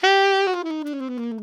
Index of /90_sSampleCDs/Giga Samples Collection/Sax/ALTO DOUBLE
ALTOLNGFLG 3.wav